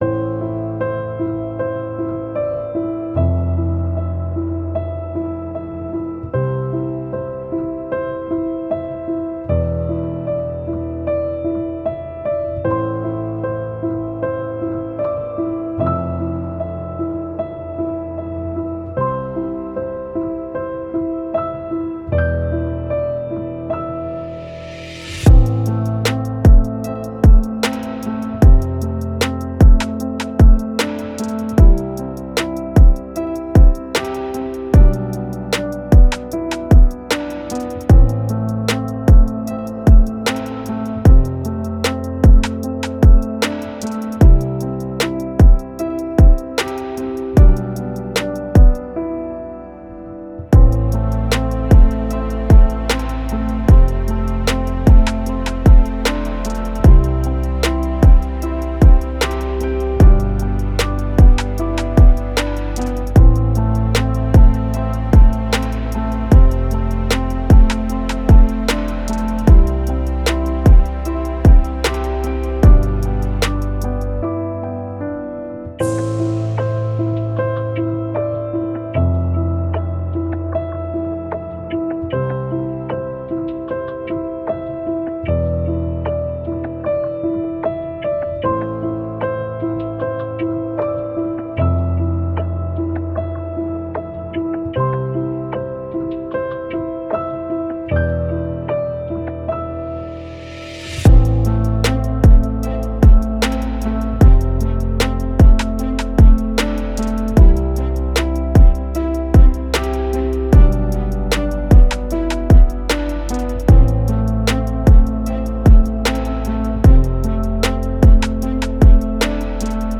HipHop-Beat - "Nostalgia"
Ich hab einen neuen HipHop-Beat produziert!